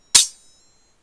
Other Sound Effects
sword_clash.8.ogg